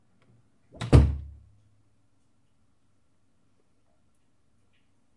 卧室木门打开关上
描述：卧室木门打开关上